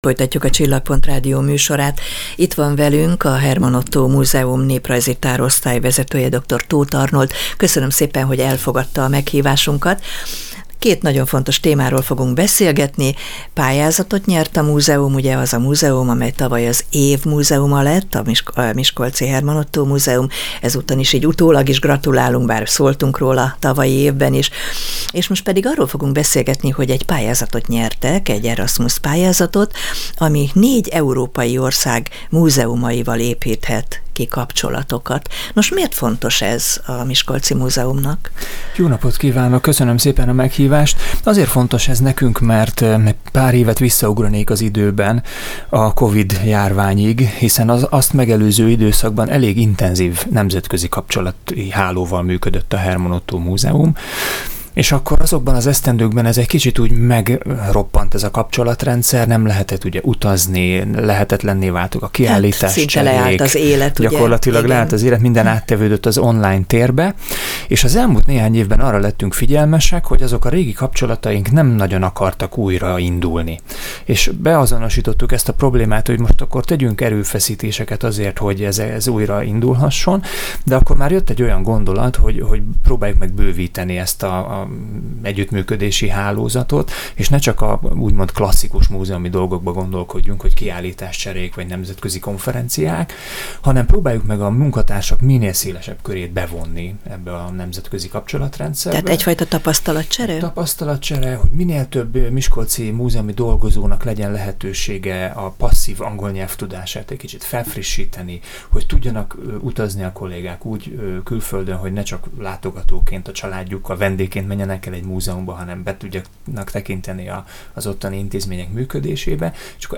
Most 4 uniós partnerrel, múzeummal vették fel a kapcsolatot, és hogy mi a cél, az kiderül a beszélgetésből.